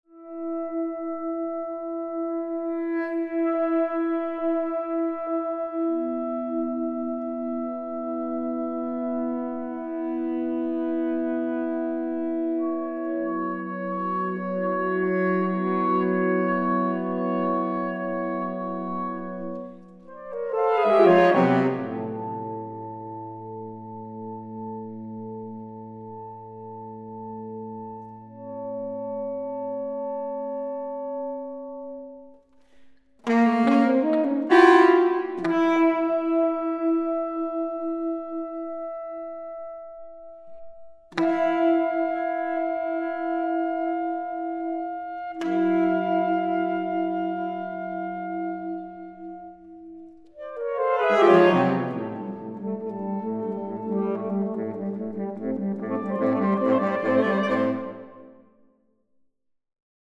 Saxophon
eine Klangreise für Saxofonquartett
Spannende Kammermusik in ausgefallenen Interpretationen!